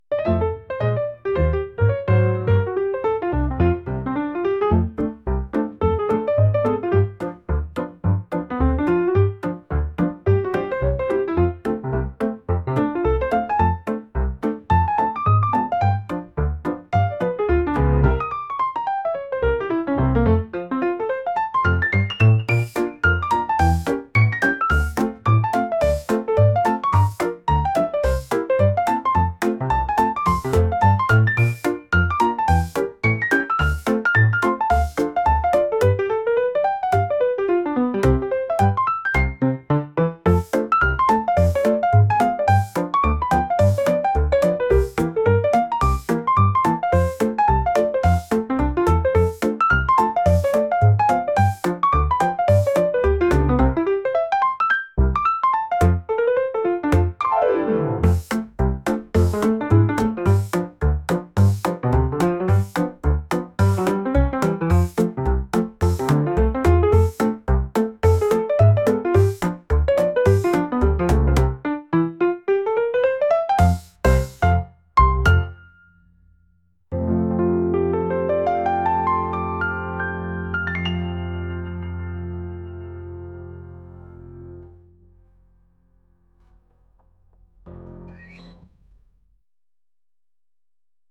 軽快なジャズ曲です。